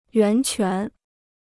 源泉 (yuán quán): fountainhead; well-spring.